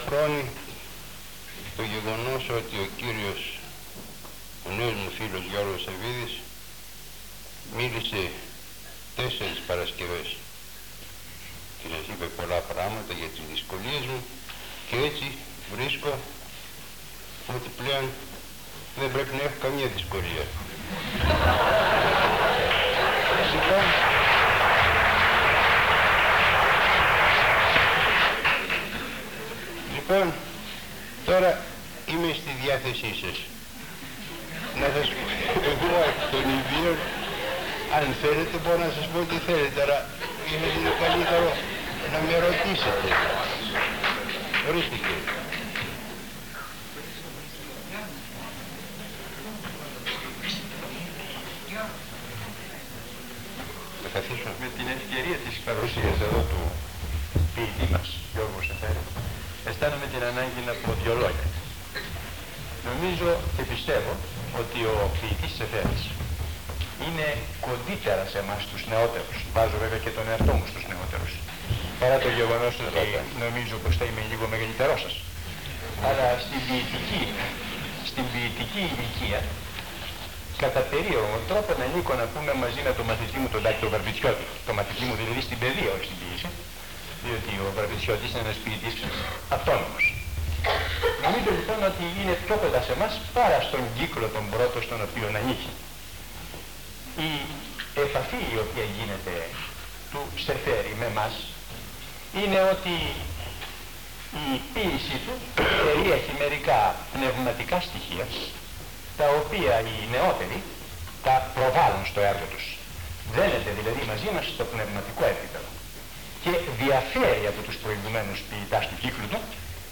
Εξειδίκευση τύπου : Εκδήλωση
Περιγραφή: Κύκλος Μαθημάτων με γενικό Θέμα "Σύγχρονοι Νεοέλληνες Ποιητές"
Περίληψη: Συζήτηση του κοινού με τον Γ. Σεφέρη